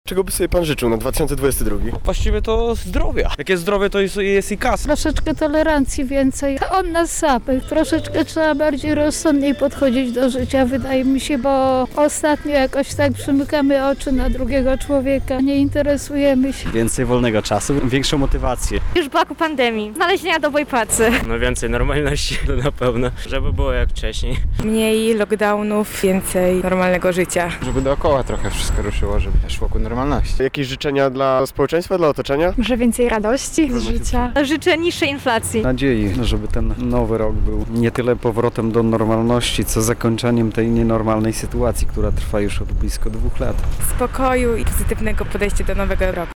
[SONDA] Czego lublinianie życzą sobie w roku 2022? - Radio Centrum